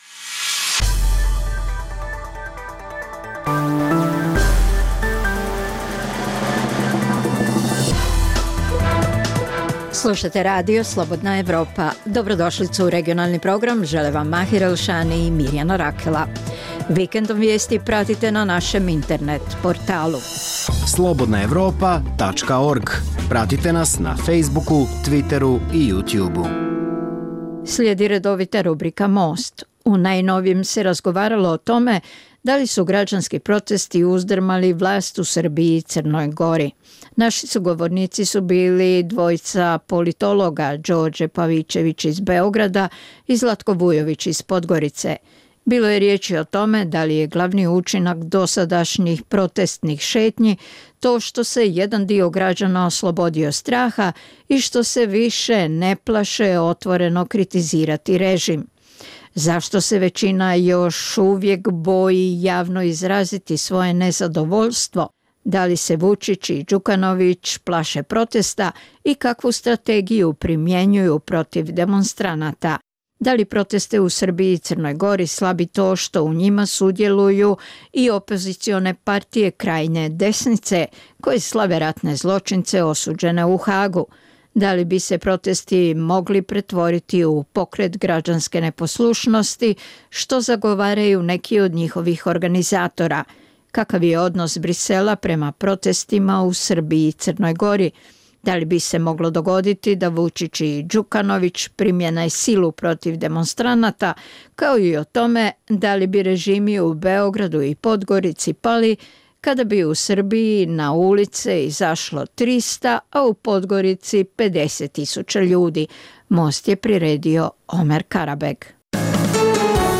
u kojem ugledni sagovornici iz regiona razmtraju aktuelne teme. Drugi dio emisije čini program "Pred licem pravde" o suđenjima za ratne zločine na prostoru bivše Jugoslavije.